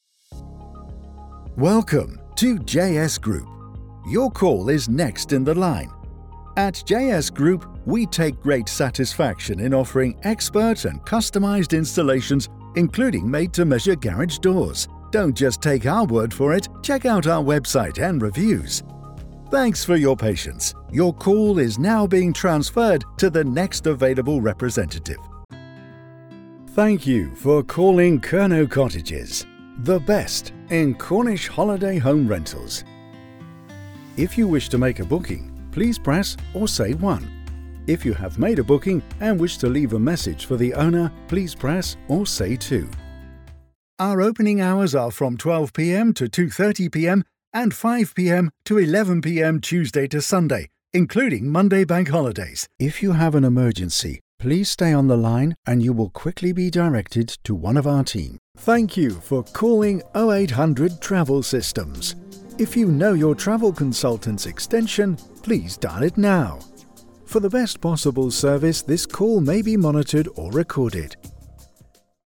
IVR
I have a natural accent free (RP) style voice which is flexible and can adapt to most voiceover projects.
Rode NT1a Condensor Mic, Mac Mini m4, Adobe Audition CC, Scarlett Solo Interface, Tannoy 405 Studio monitors, Sennheiser HD 280 Pro monitoring headphones.
Deep